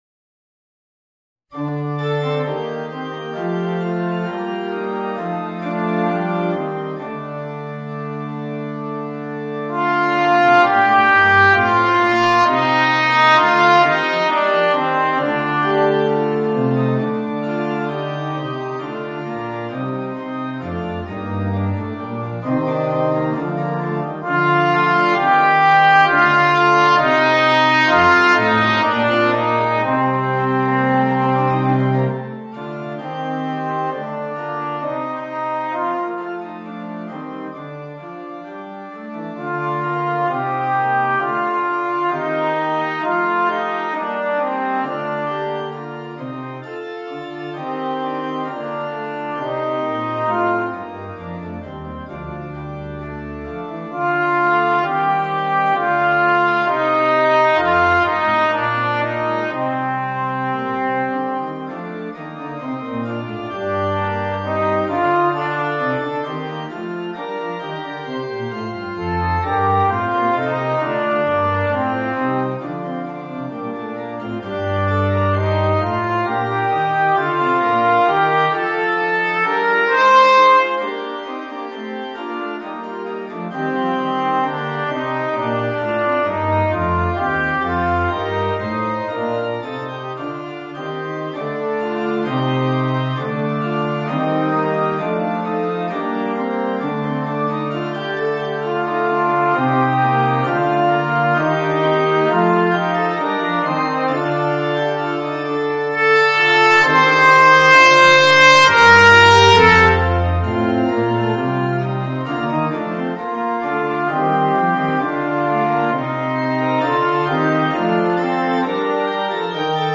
Voicing: Trumpet and Keyboard